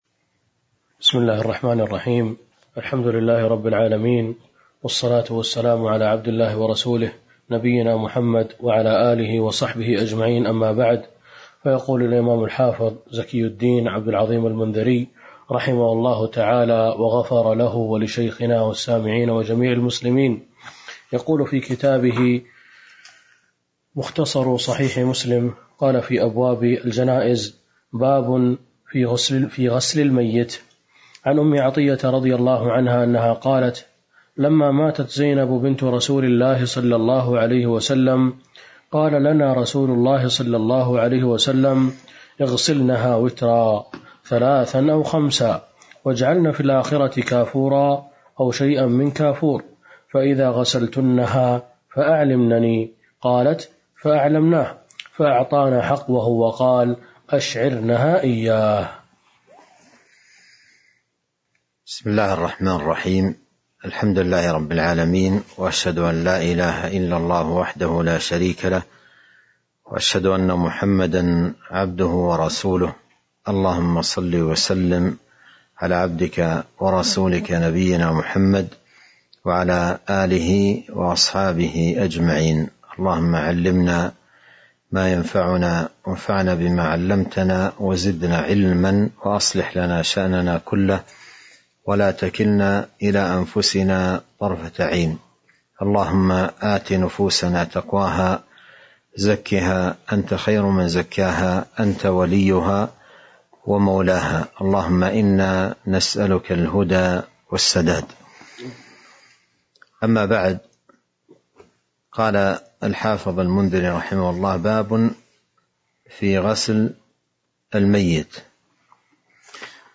تاريخ النشر ٣٠ جمادى الآخرة ١٤٤٢ هـ المكان: المسجد النبوي الشيخ: فضيلة الشيخ عبد الرزاق بن عبد المحسن البدر فضيلة الشيخ عبد الرزاق بن عبد المحسن البدر باب في غسل الميت (05)